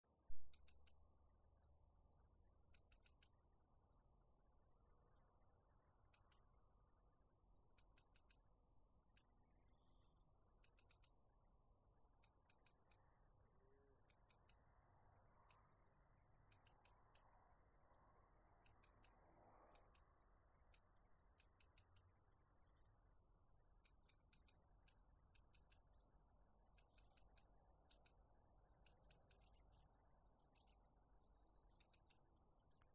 Black Redstart, Phoenicurus ochruros
StatusAgitated behaviour or anxiety calls from adults